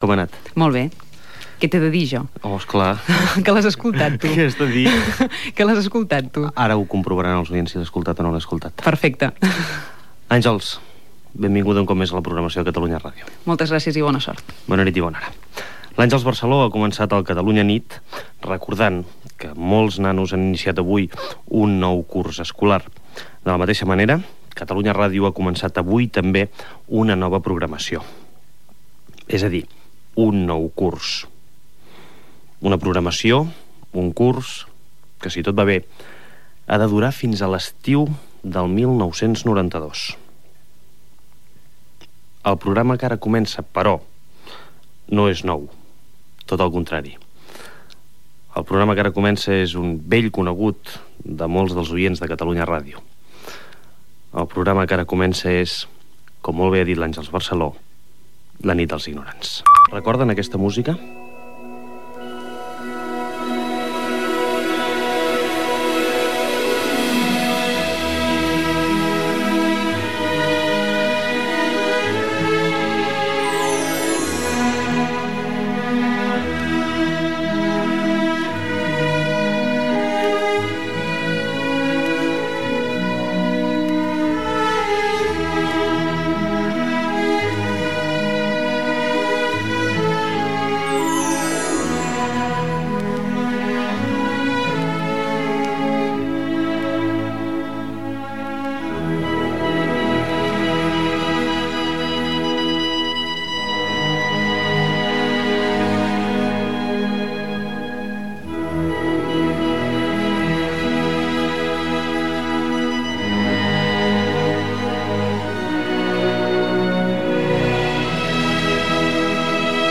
Extracte de l'inici de la primera edició de la temporada 1991-92. Presentació del programa, sensacions de l'inici de la temporada, trucada telefònica.
FM